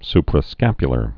(sprə-skăpyə-lər)